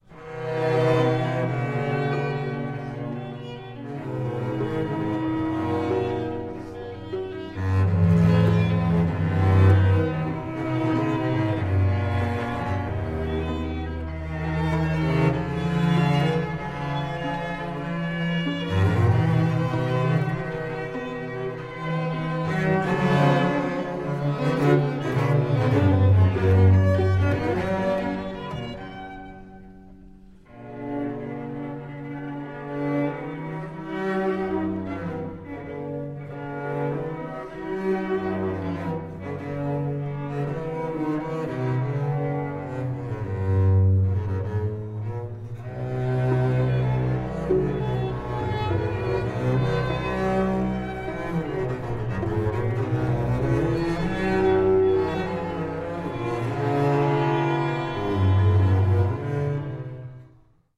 violin
viola
cello
double bass